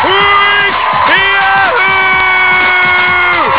winkyahoooo.wav